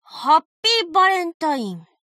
贡献 ） 协议：Copyright，其他分类： 分类:伏特加(赛马娘 Pretty Derby)语音 您不可以覆盖此文件。